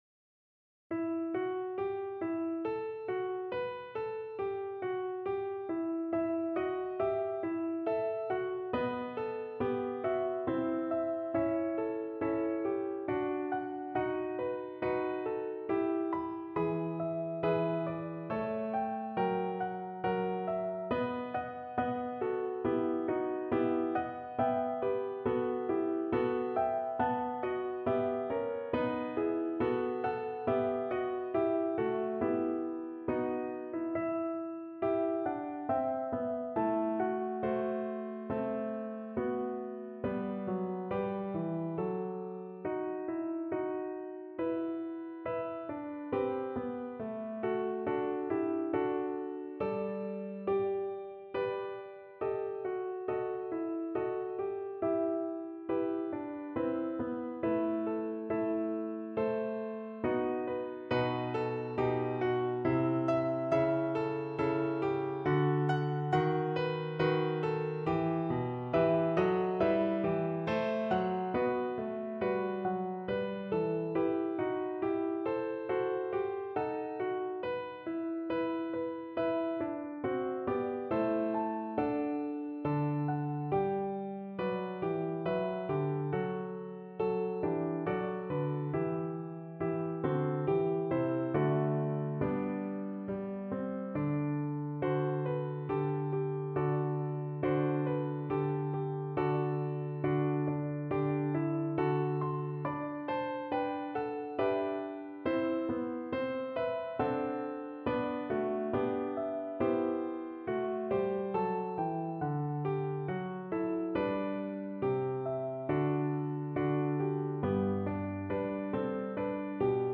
Free Sheet music for Mezzo Soprano Voice
Play (or use space bar on your keyboard) Pause Music Playalong - Piano Accompaniment Playalong Band Accompaniment not yet available transpose reset tempo print settings full screen
3/4 (View more 3/4 Music)
E minor (Sounding Pitch) (View more E minor Music for Mezzo Soprano Voice )
= 69 Largo
Classical (View more Classical Mezzo Soprano Voice Music)